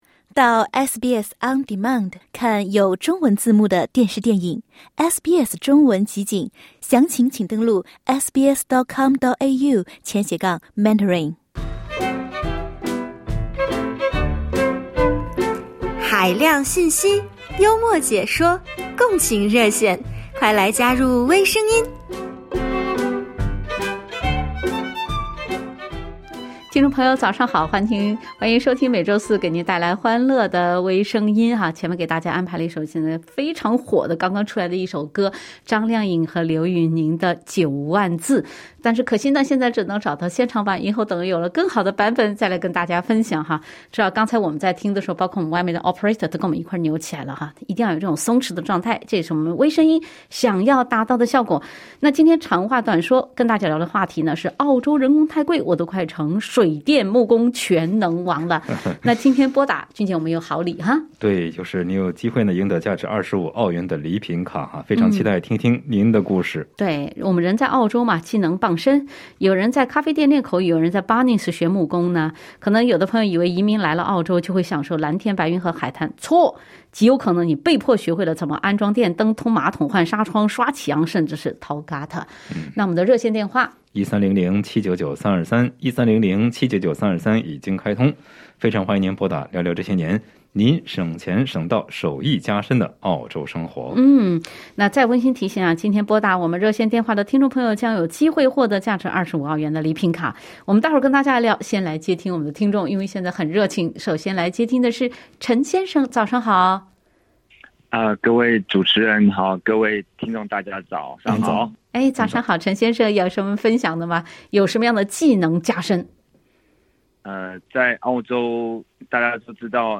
掏马蜂窝、刷油漆、掏Gutter，听众踊跃发言，热情参与分享这些年自己“省钱省到手艺加身”的澳洲生活。